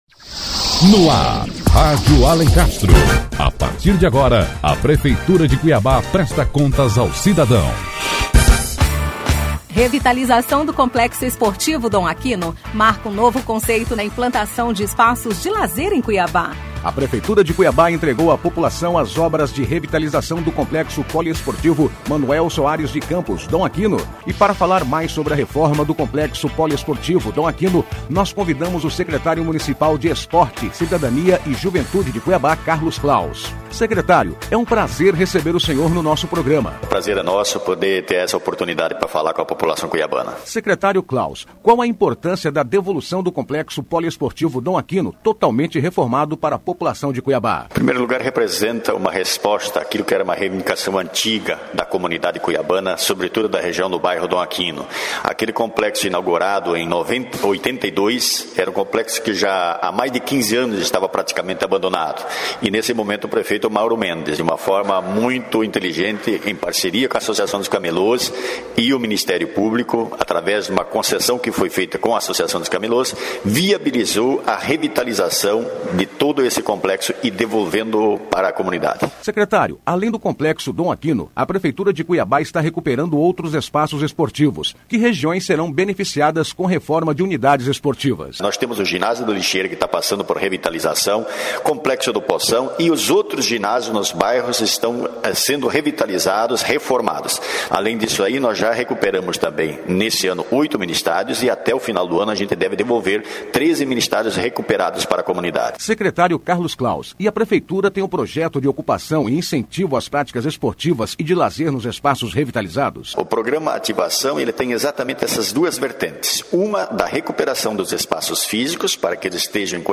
Notícias / 147º Programa 07 de Outubro de 2014 16h46 Revitalização do Complexo Dom Aquino A revitalização do Complexo Manoel Soares de Campos "Dom Aquino" marca um novo conceito na criação de espaços noturnos de lazer em Cuiabá. E para falar mais detalhes sobre o conjunto de ações que o novo espaço permitirá realizar, convidamos o Secretário Municipal de Esportes, Cidadania e Juventude, Carlos Klaus.